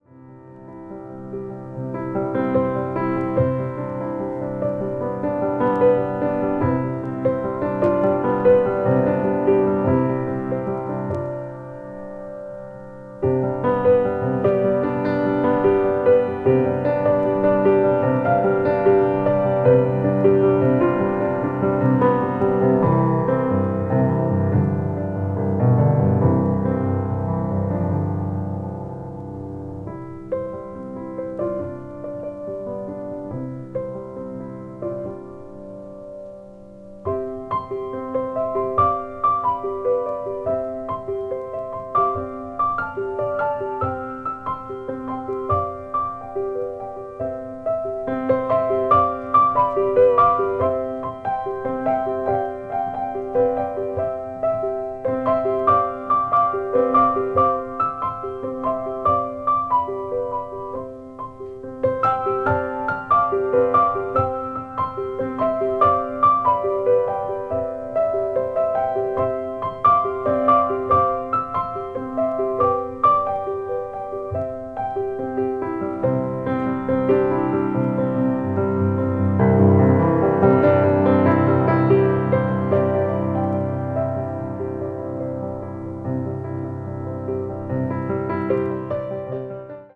acoustic   new age   piano solo